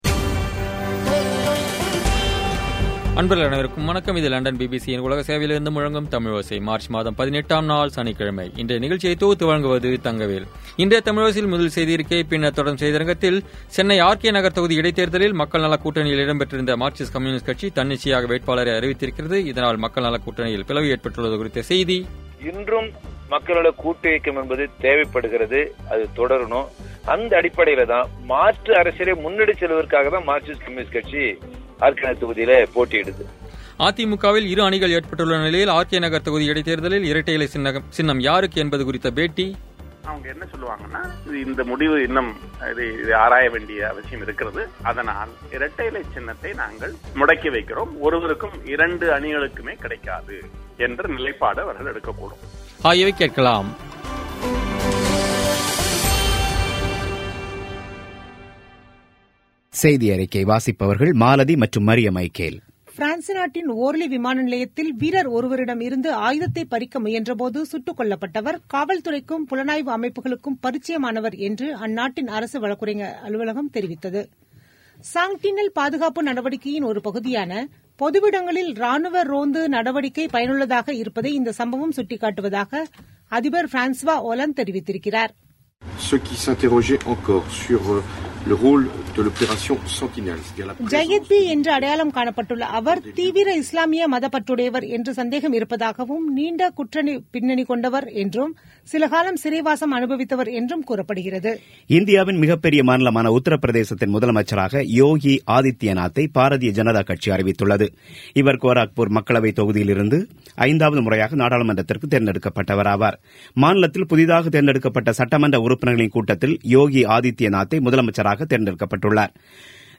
இன்றைய தமிழோசையில், சென்னை ஆர்.கே. நகர் தொகுதி இடைத் தேர்தலில், மக்கள் நலக்கூட்டணியில் இடம் பெற்றிருந்த மார்க்சிஸ்ட் கம்யூனிஸ்ட் கட்சி தன்னிச்சையாக வேட்பாளரை அறிவித்திருக்கிறது. இதனால், மக்கள் நலக்கூட்டணியில் பிளவு ஏற்பட்டுள்ளது குறித்த செய்தி அதிமுகவில் இரு அணிகள் ஏற்பட்டுள்ள நிலையில், ஆர்.கே. நகர் தொகுதி இடைத் தேர்தலில் இரட்டை இலைச் சின்னம் எந்த அணிக்குக் கிடைக்கும் என்பது குறித்த பேட்டி ஆகியவை கேட்கலாம்.